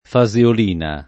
faseolina [ fa @ eol & na ]